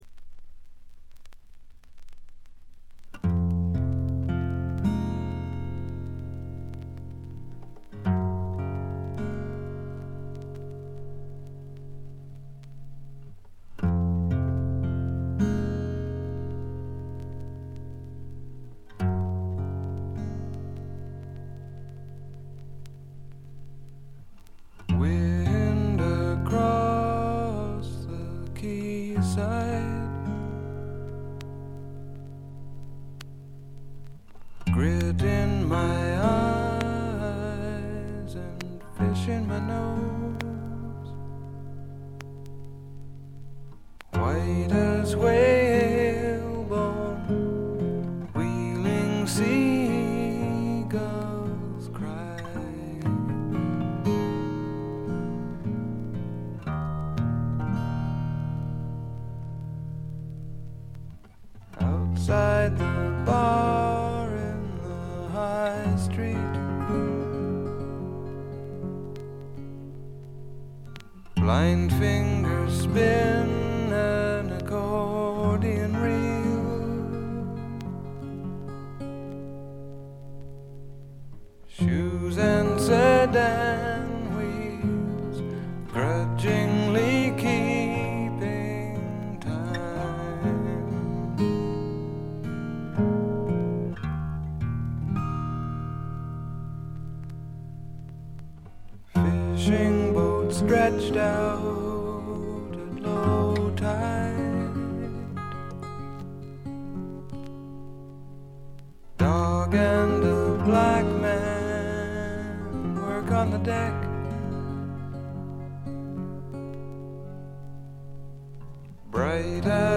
ところどころでチリプチ。
静謐で内省的なホンモノの歌が聴ける名作です。
ほとんどギターの弾き語りで、ギターインストのアコギの腕前も素晴らしいです。
試聴曲は現品からの取り込み音源です。